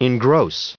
added pronounciation and merriam webster audio
1301_engross.ogg